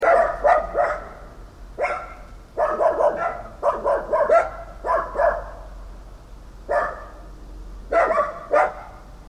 دانلود صدای سگ برای کودکان از ساعد نیوز با لینک مستقیم و کیفیت بالا
جلوه های صوتی